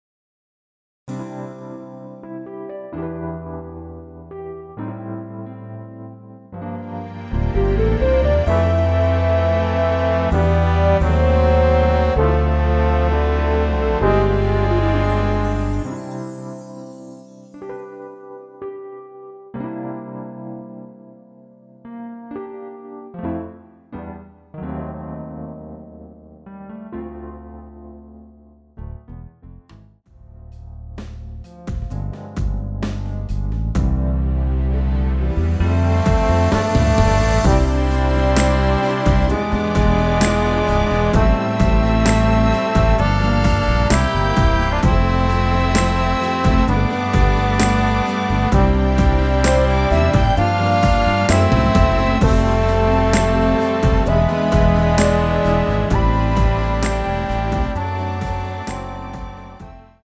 원키에서(-3)내린 MR 입니다.(미리듣기 참조)
앞부분30초, 뒷부분30초씩 편집해서 올려 드리고 있습니다.
중간에 음이 끈어지고 다시 나오는 이유는